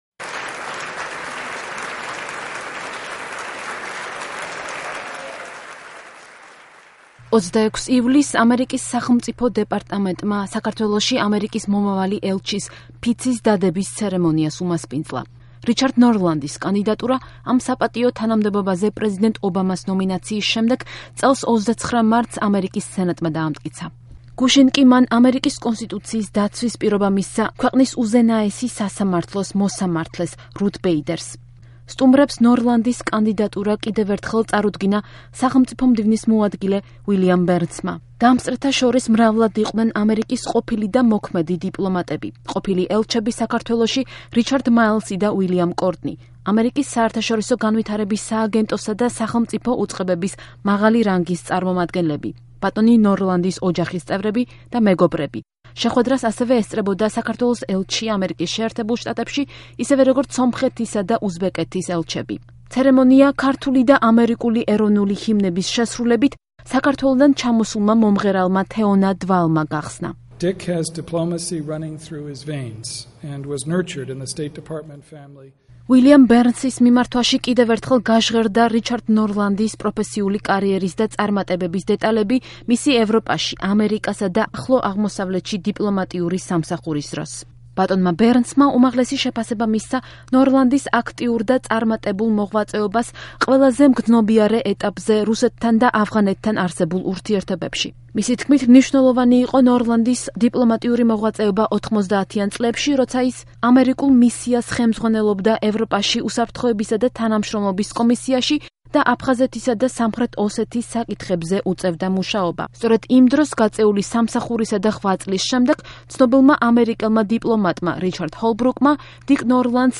ამერიკის სახელმწიფო დეპარტამენტმა საქართველოში ამერიკის მომავალი ელჩის ფიცის დადების ცერემონიას უმასპინძლა